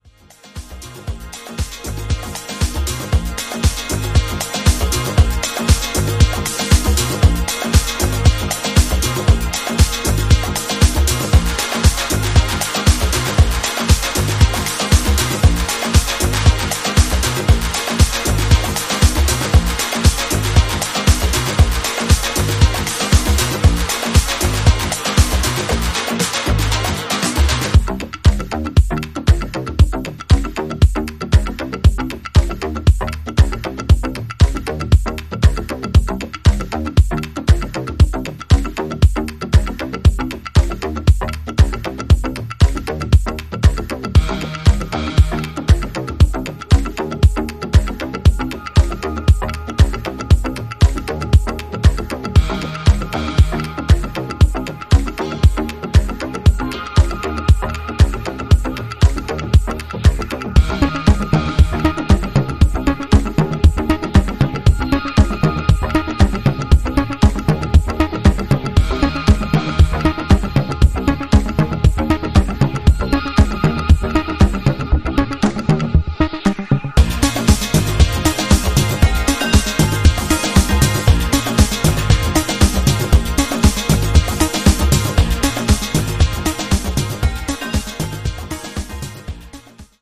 バレアリックやアシッド等の要素をセンス良くブレンドさせた、